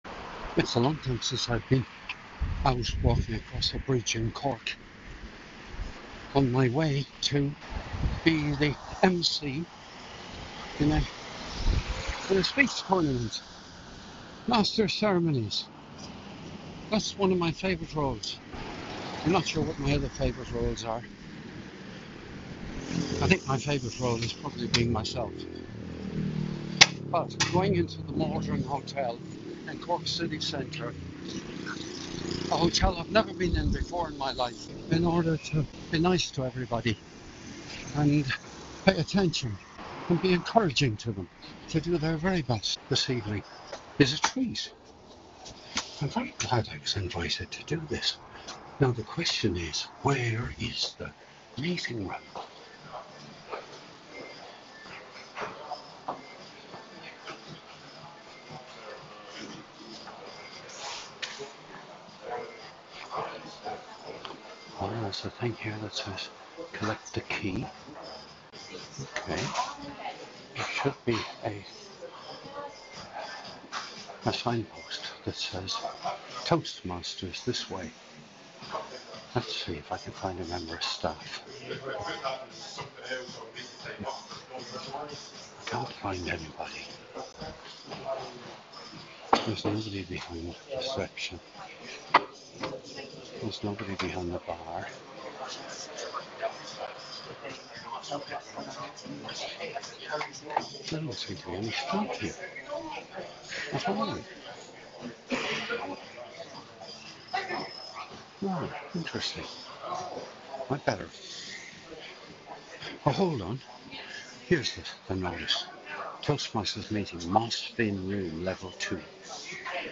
This was recorded as I was falling asleep on Sunday 19th of January 2025